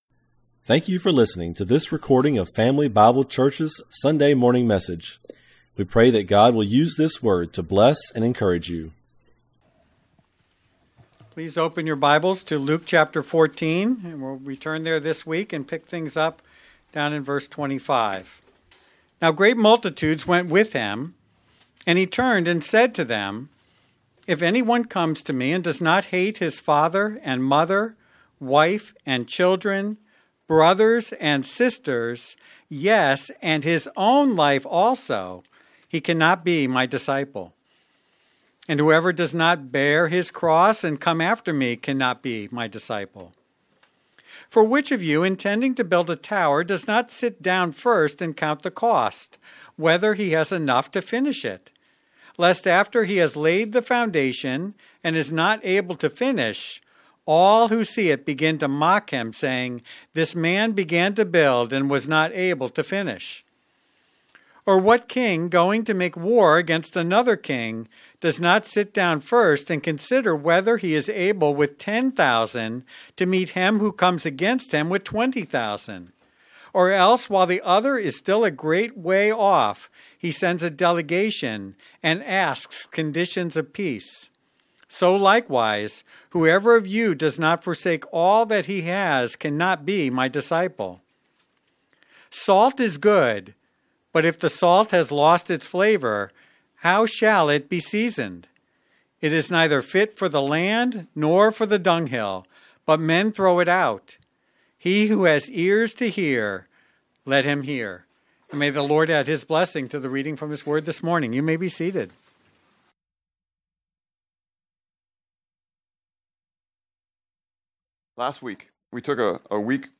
Family Bible Church Message From 12 January 2025